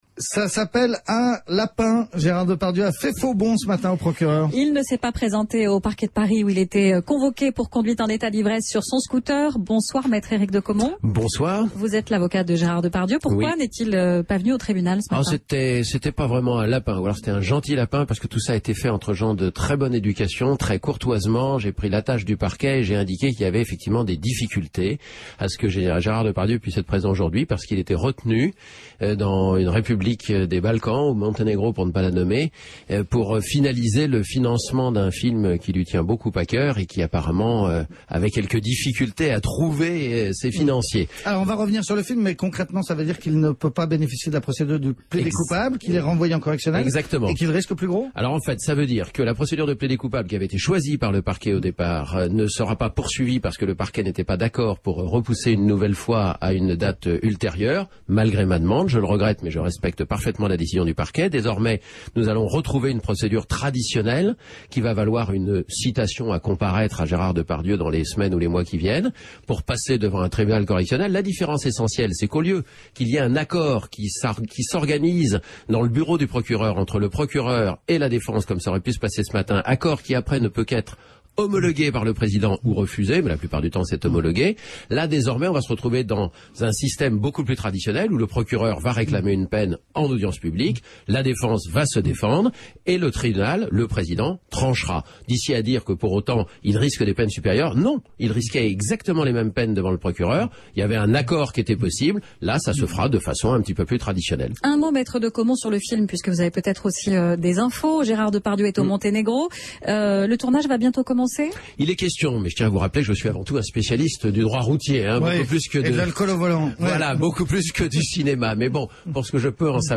Interview sur Europe 1 le 8 janvier 2013 – Affaire Depardieu